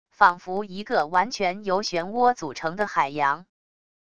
仿佛一个完全由漩涡组成的海洋wav音频